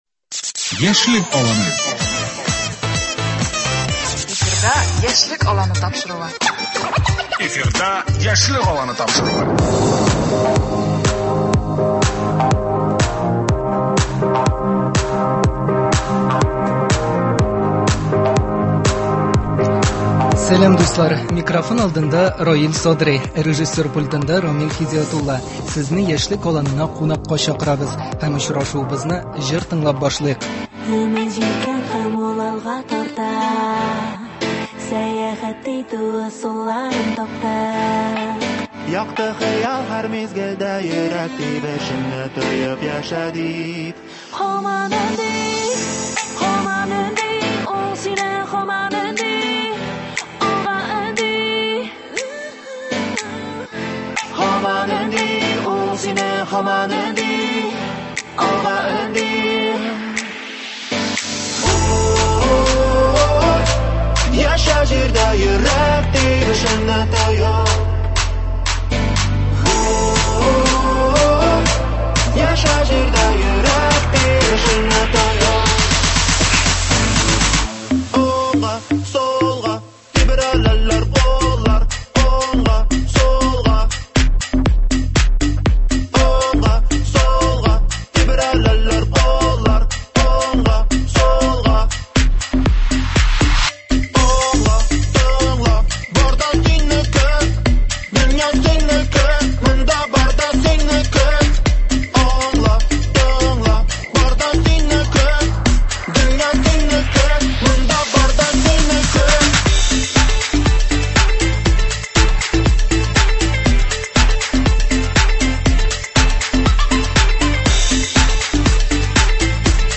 Студиябездә бүген “Сәләт” яшьләр үзәгеннән кунаклар булачак.